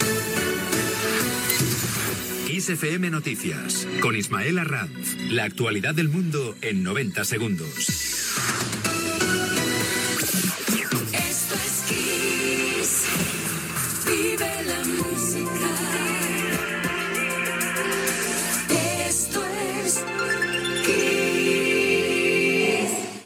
Careta de sortida de les notícies